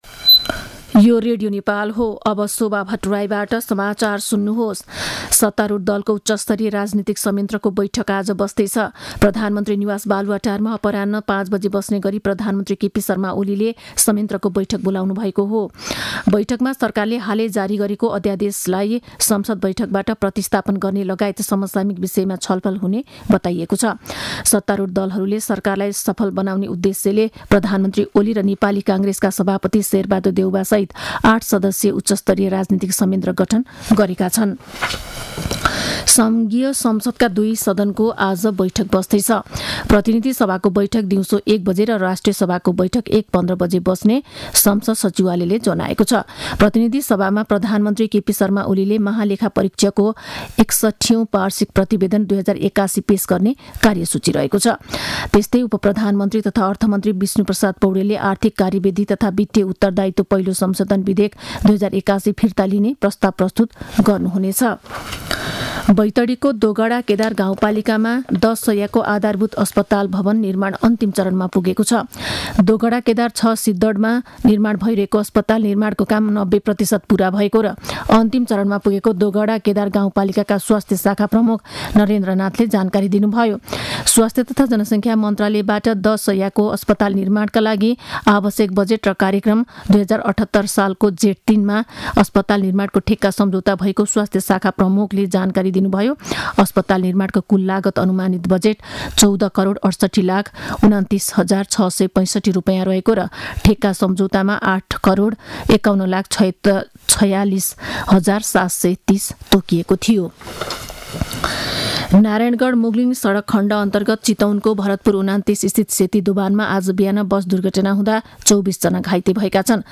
मध्यान्ह १२ बजेको नेपाली समाचार : २८ माघ , २०८१